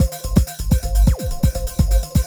BEEPER    -R.wav